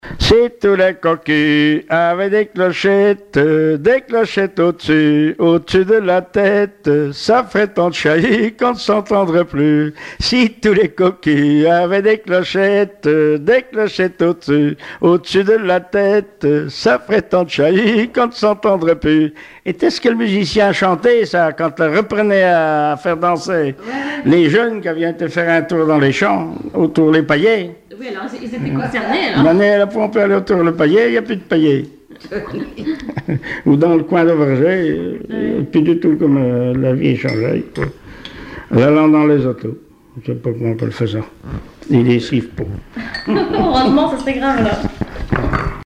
Genre strophique
Témoignages et chansons traditionnelles et populaires
Pièce musicale inédite